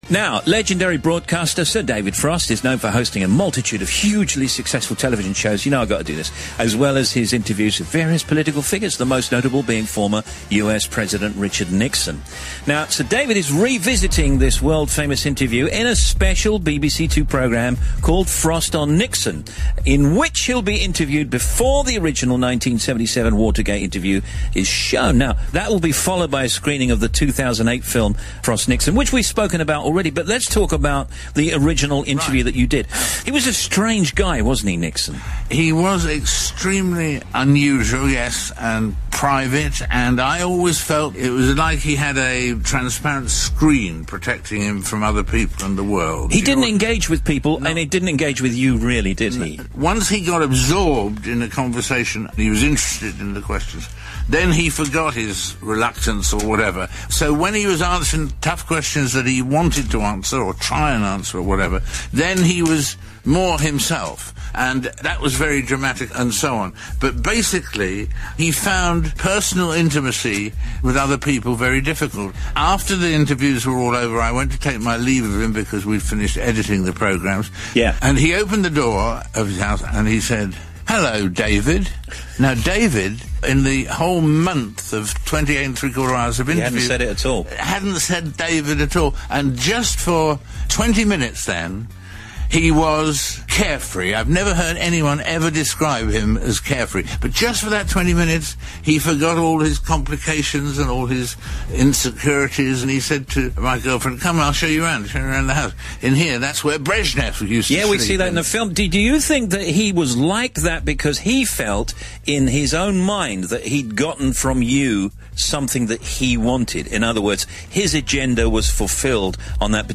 Here David Frost recalls the infamous 1977 interview with disgraced former President Nixon on Steve Wright’s Radio 2 afternoon show in September 2011.